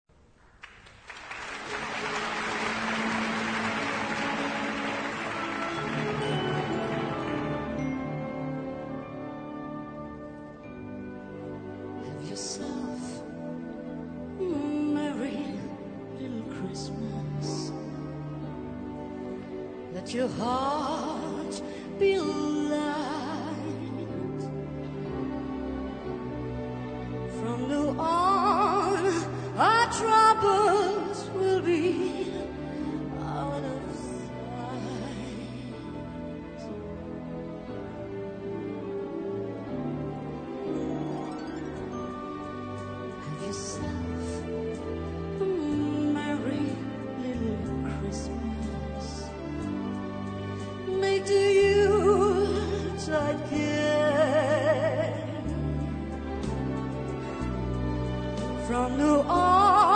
key: G-major